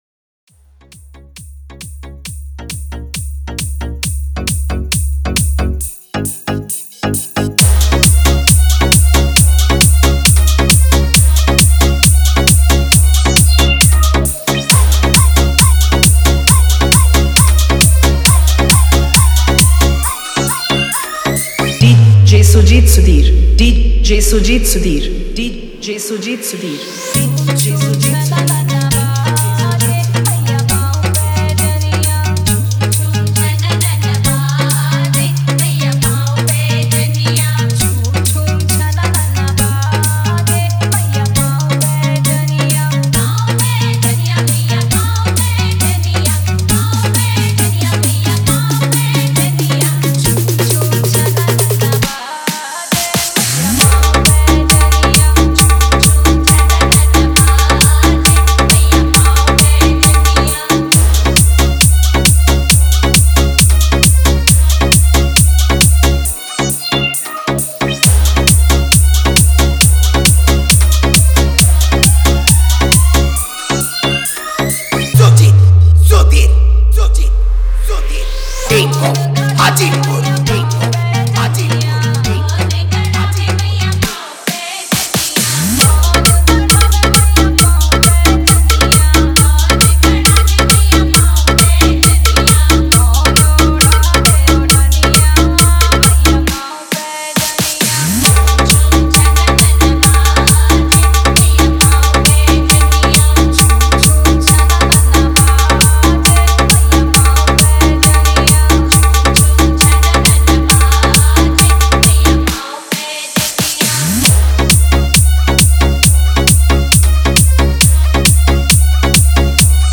Navratri Dj Song